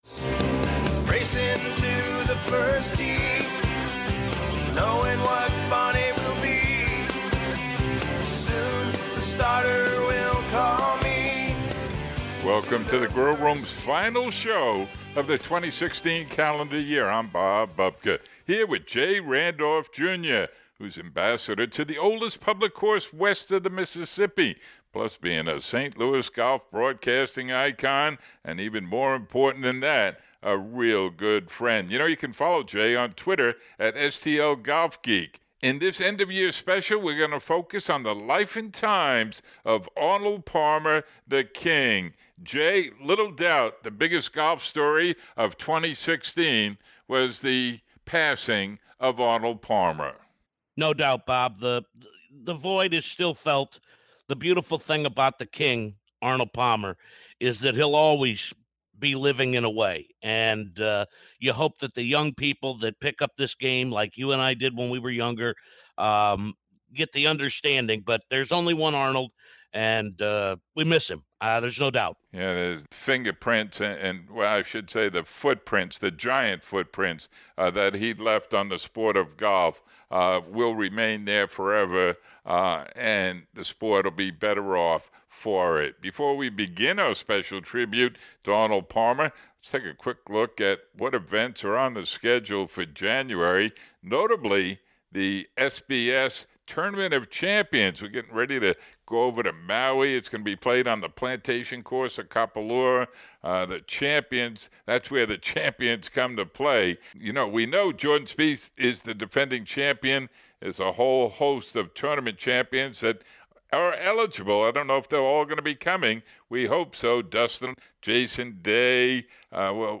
And finally, Mr. Palmer himself shares some stellar advice on why he was so successful.